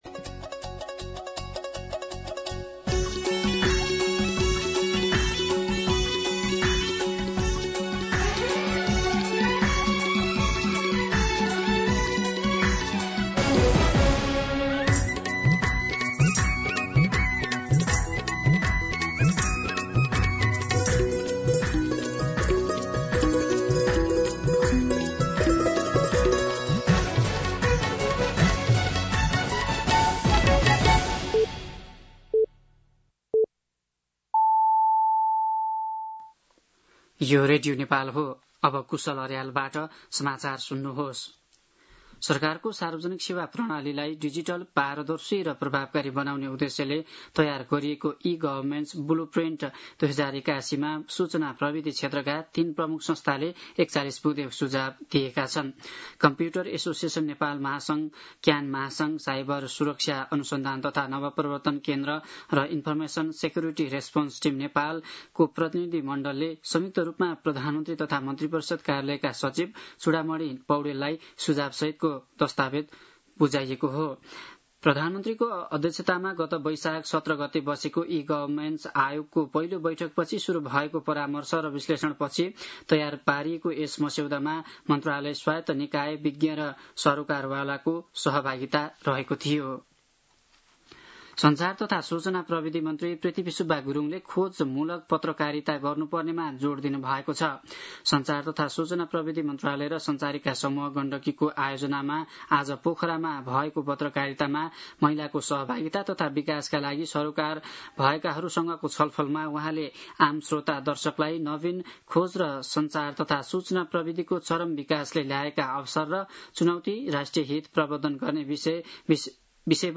दिउँसो ४ बजेको नेपाली समाचार : १० जेठ , २०८२
4-pm-Nepali-News-4.mp3